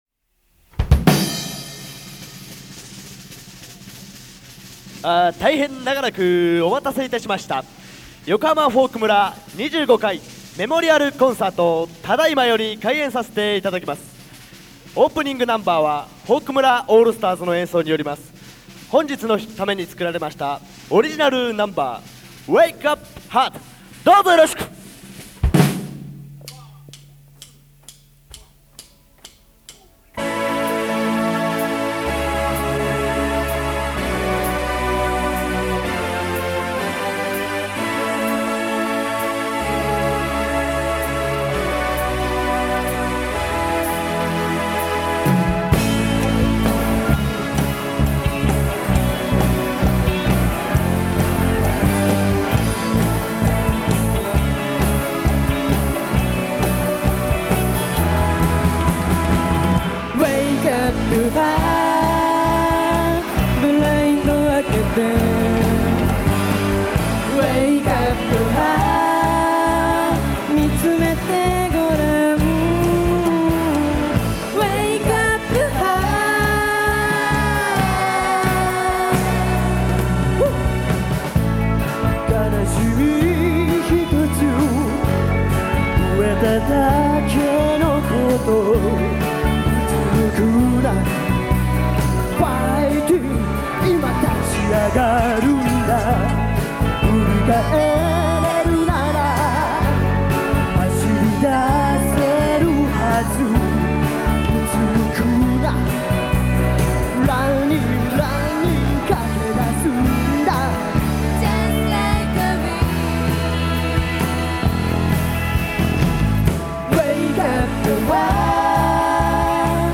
1985年9月22日に行われた記念コンサート
オープニングでみんなで歌った「Wake Up Heart」